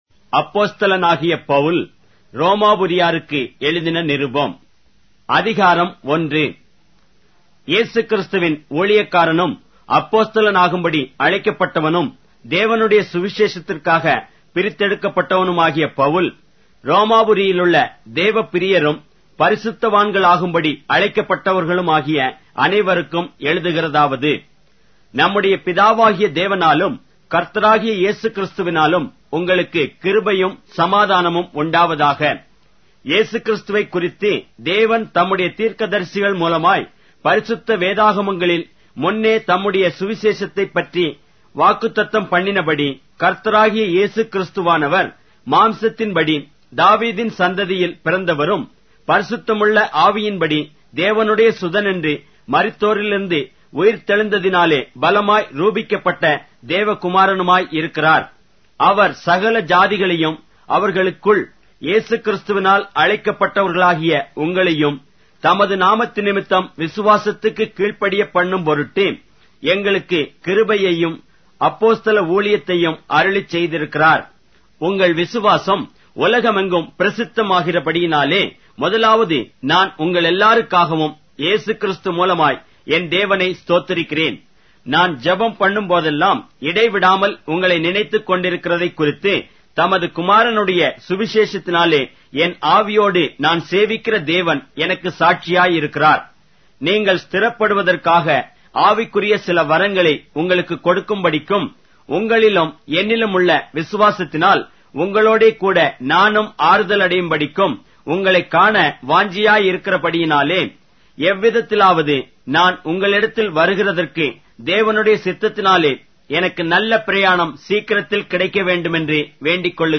Tamil Audio Bible - Romans 10 in Tev bible version